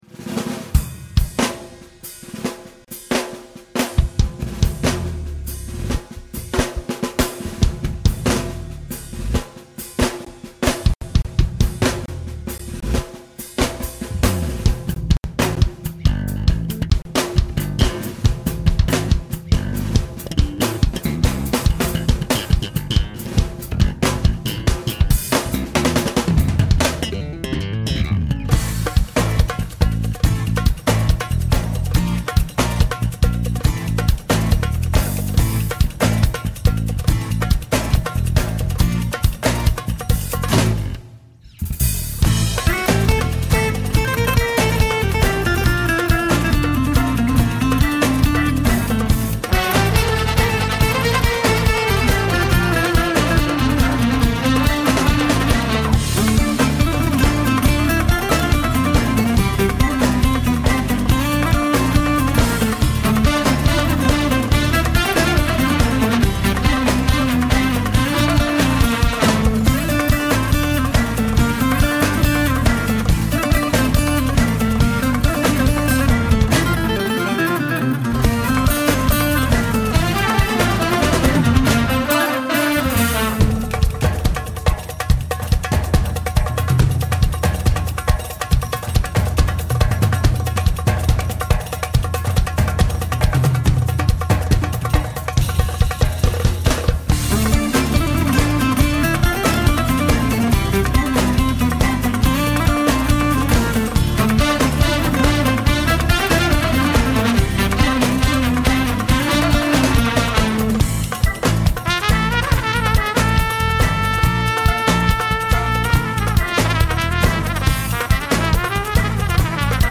Genre: Ethnic / Folk - pop / Ethnic jazz